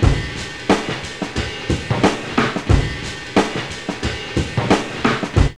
JAZZLP4 90.wav